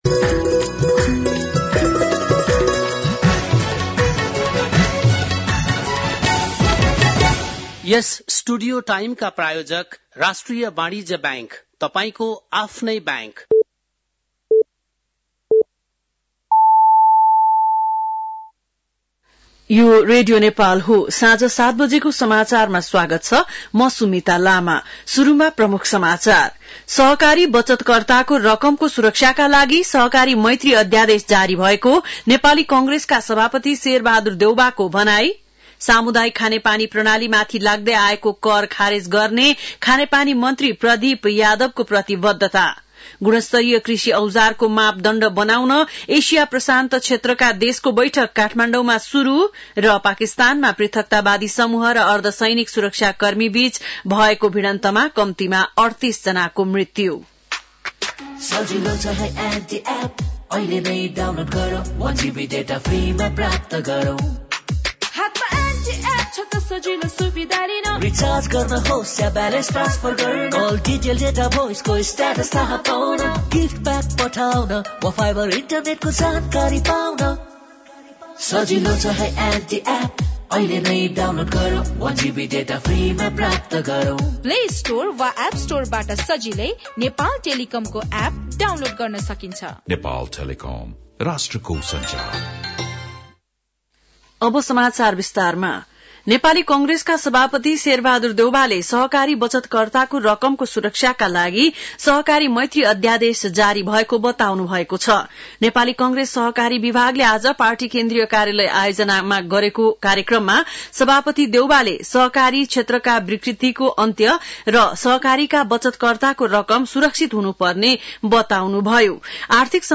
बेलुकी ७ बजेको नेपाली समाचार : २० माघ , २०८१